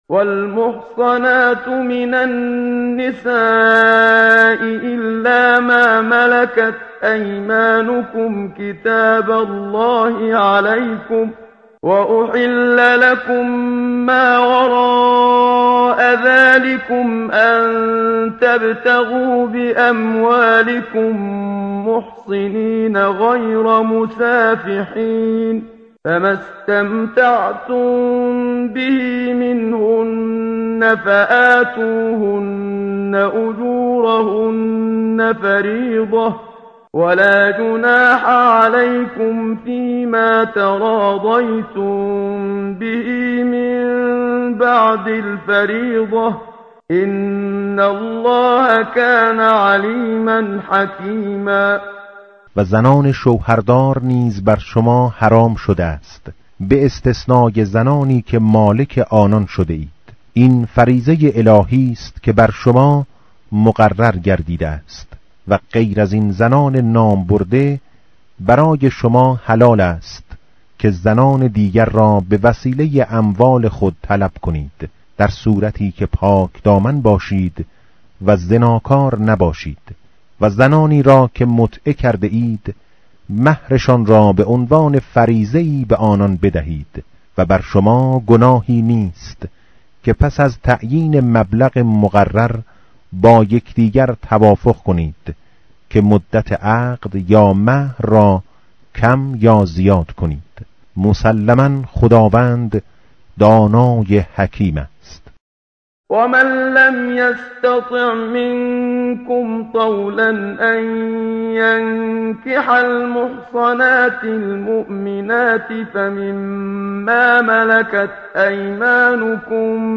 متن قرآن همراه باتلاوت قرآن و ترجمه
tartil_menshavi va tarjome_Page_082.mp3